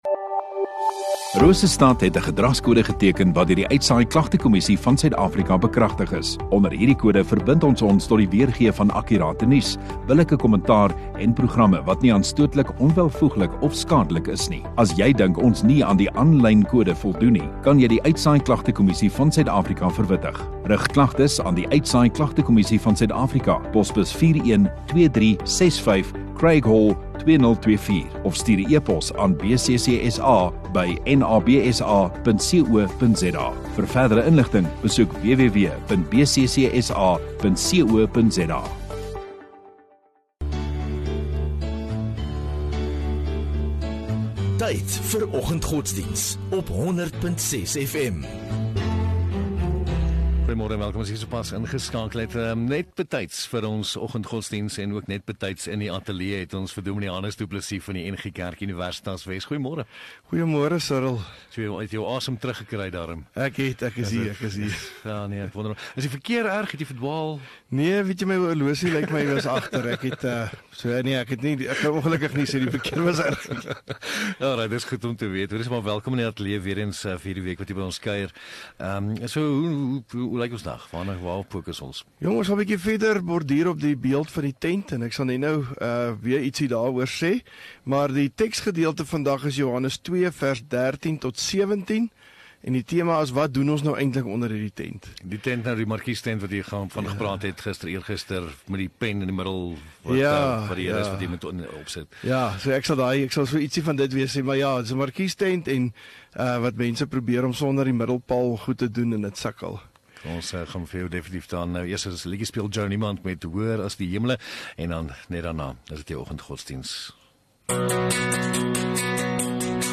14 Mar Donderdag Oggenddiens